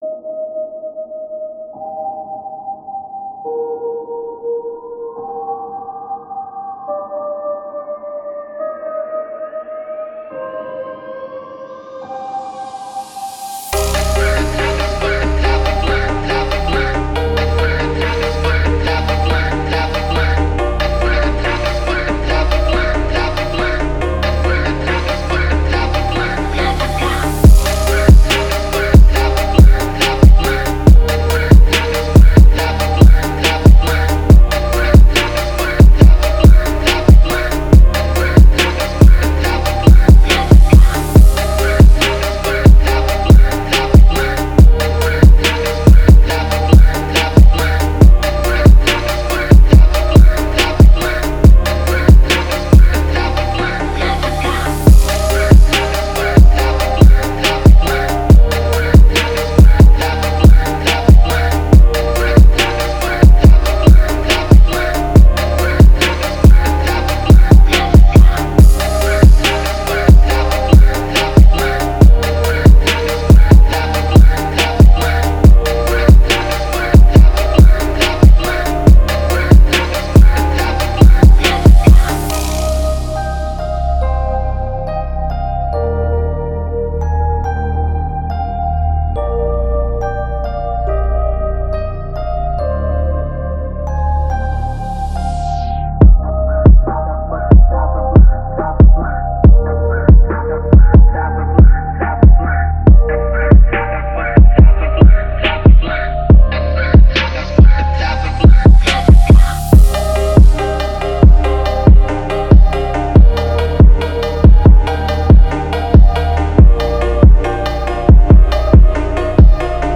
Фонк музыка
медленный фонк